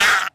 There is only one sound effect in the game and it's the unused hurt sound from the original 5b.
5b_unused_hurt_sound.mp3